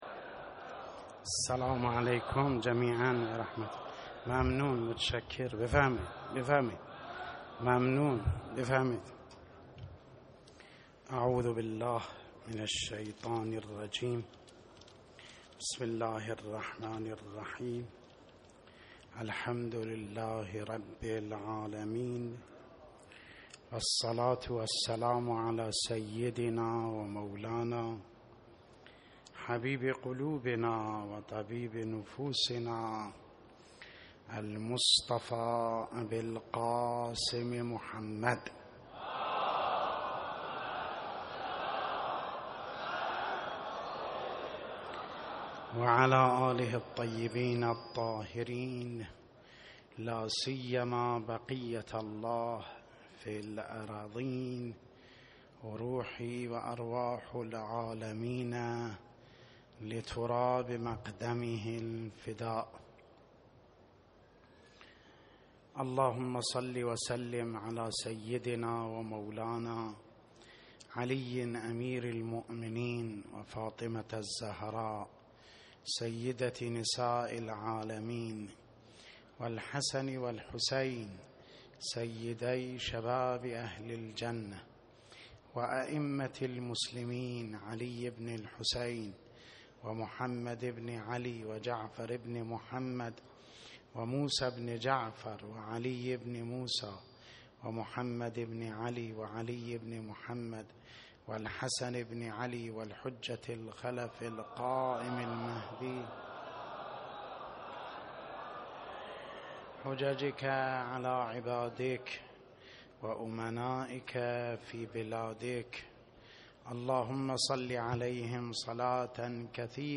خطبه اول